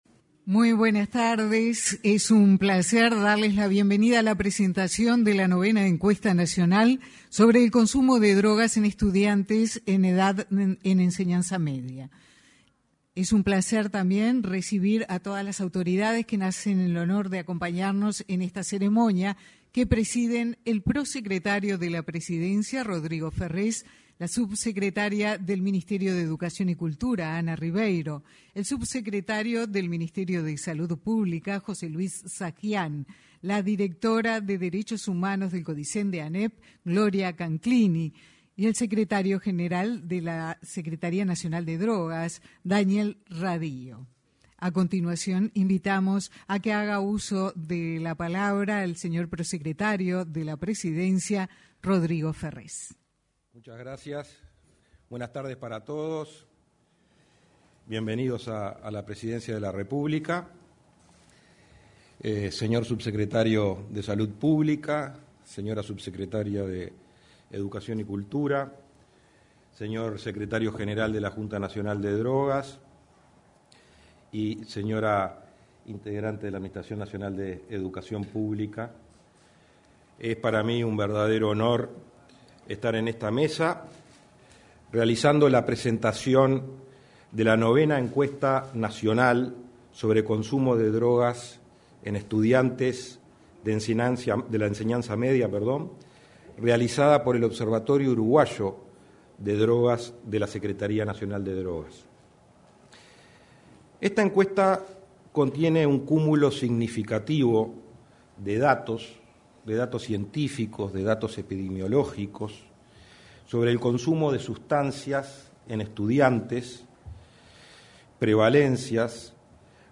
Presentación de la IX Encuesta Nacional sobre Consumo de Drogas en Estudiantes de Enseñanza Media
Participaron el prosecretario de la Presidencia, Rodrigo Ferrés; el subsecretario de Salud Pública, José Luis Satdjian, y el secretario general de la Junta Nacional de Drogas, Daniel Radío.